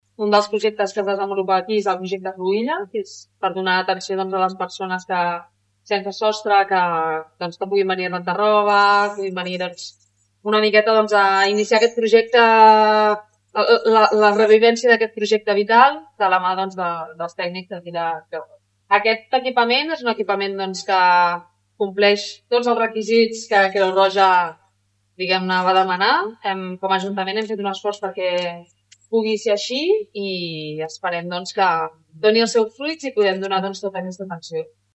Visita institucional aquest matí a la nova ubicació de l’equipament de Creu Roja Baix Llobregat Nord, situat a la finca de la Torrassa.
Cristina Dalmau, regidora Benestar Social